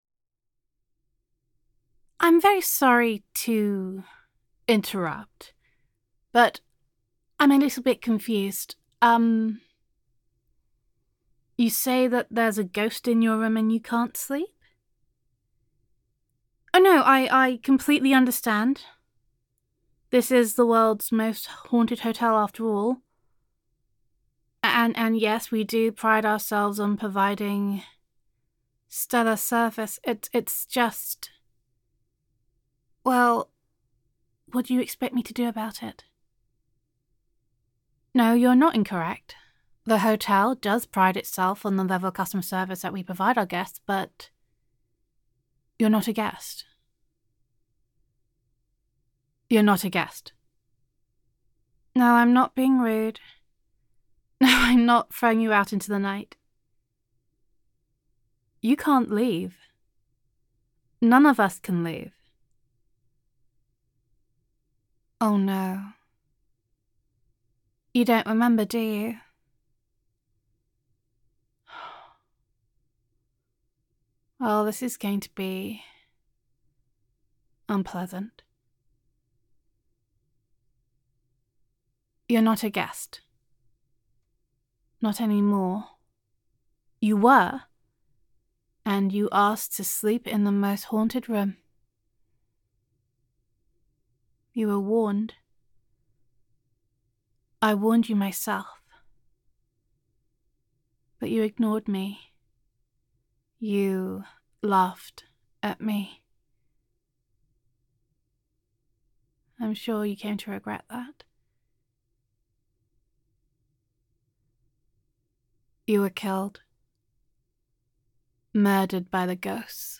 [F4A] The Haunted Hotel [Ghost Roleplay][Realisation][Gender Neutral][You Can Check Out Any Time You Like, But You Can Never Leave]